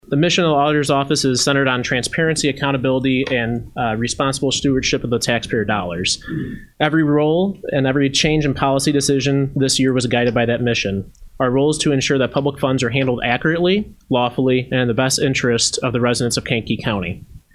During Thursday morning’s meeting of the Kankakee County Board’s Finance Committee, County Auditor Colton Ekhoff gave the committee a rundown of some of the activities from his office in 2025.